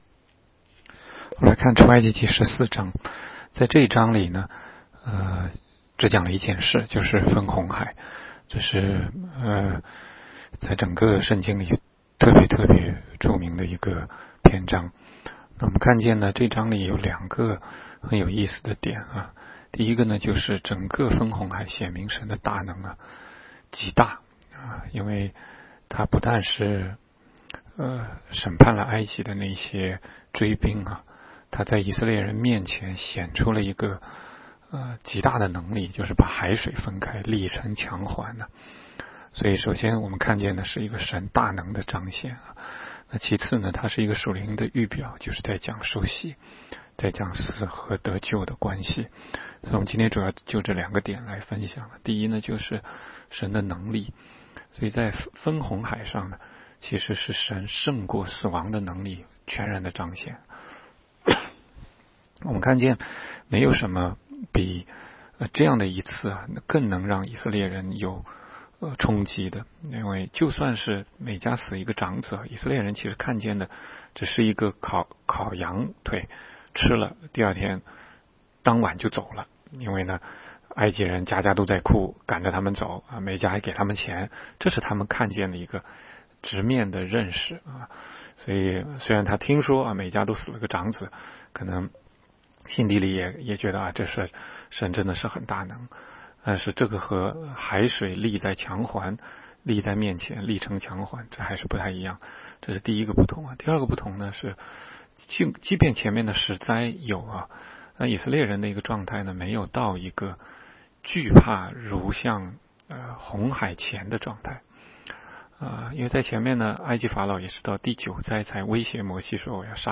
16街讲道录音 - 每日读经-《出埃及记》14章